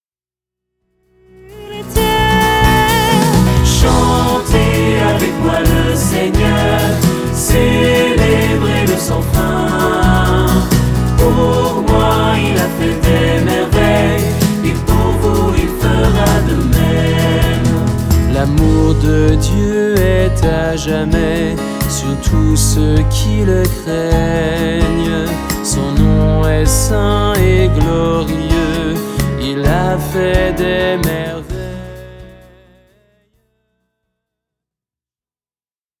Célèbres chants de Louange